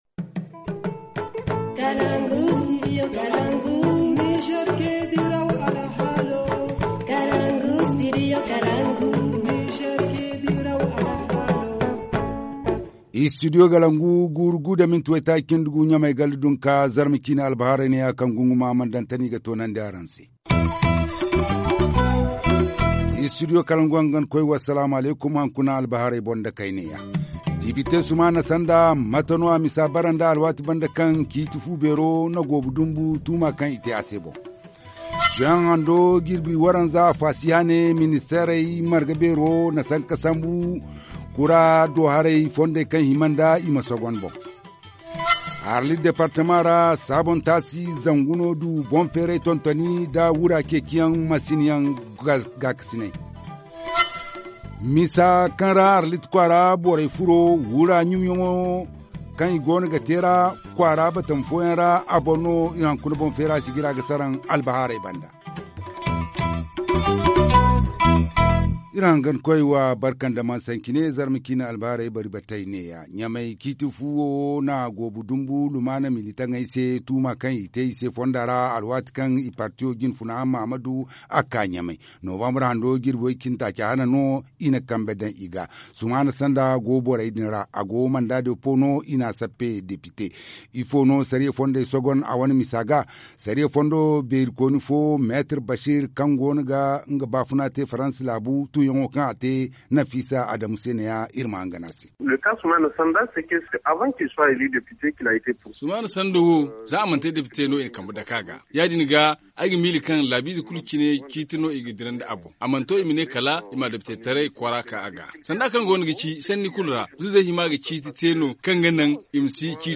journal du 14 juillet 2016 - Studio Kalangou - Au rythme du Niger